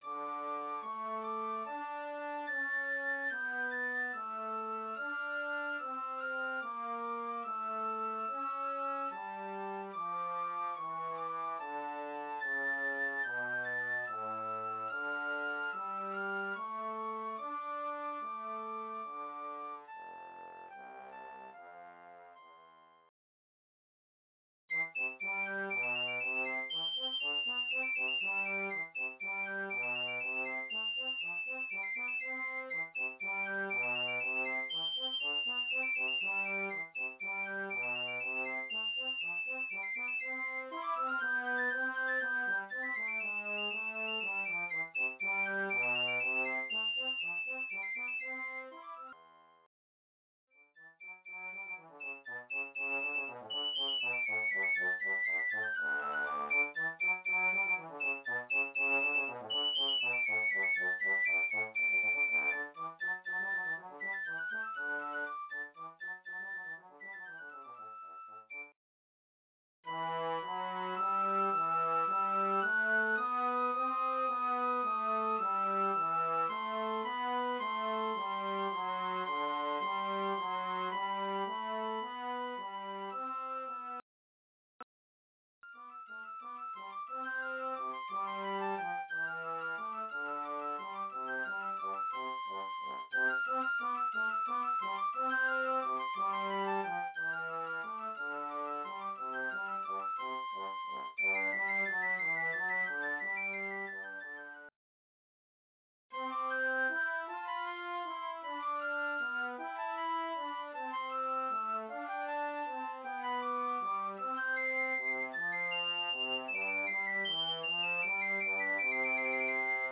Voicing: Mixed Ensemble